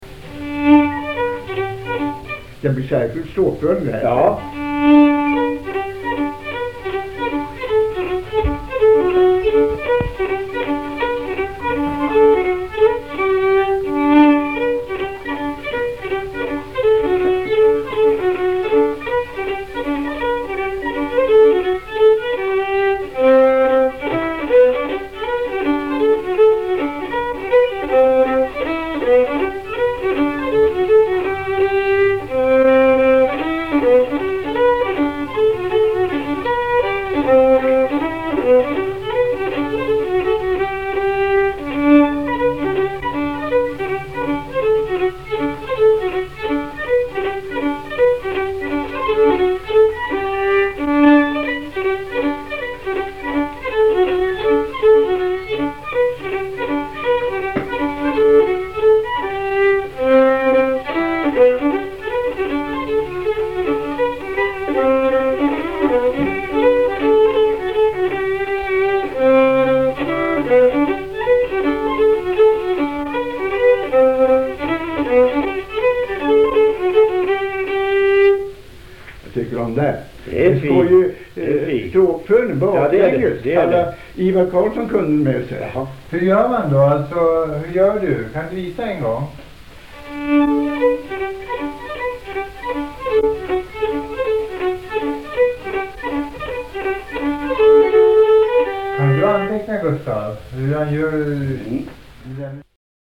Polska efter morfar, en melodi med speciell stråkföring.